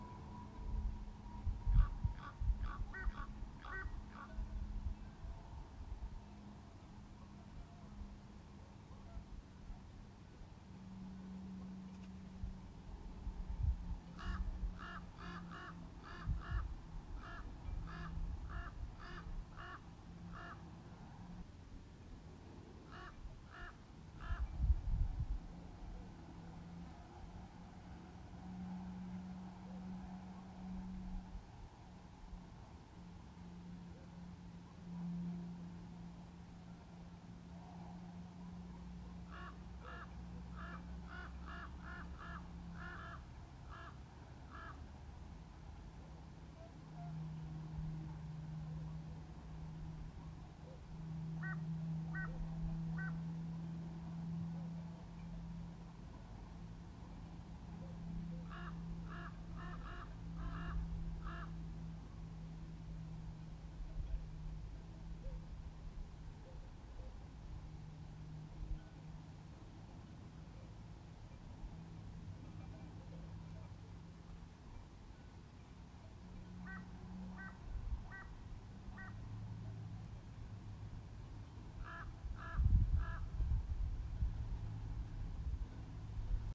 L’installazione include anche un piccolo lavoro sonoro messo all’interno della didascalia, che si chiama ‘commentatori di opere’.
The installation also includes a small sound piece placed inside the caption, called “commentators of works”.